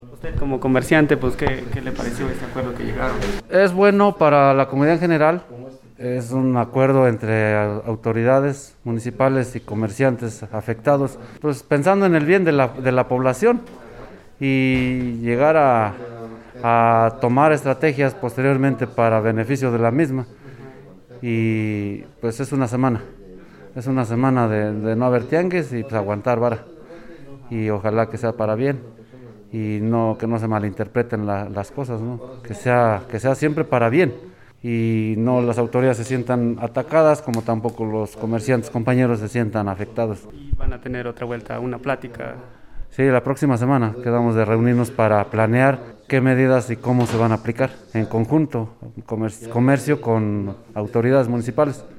El nuevo oficio elaborado se hace mención sobre el compromiso de reunirse nuevamente el próximo lunes 13 de septiembre a las 11:00 de la mañana, para que en conjunto de comerciantes y Ayuntamiento se tomen medidas preventivas para el siguiente fin de semana. Escuchemos la palabra de uno de los comerciantes decirnos su punto de vista sobre este nuevo acuerdo llegado.
Comerciante.mp3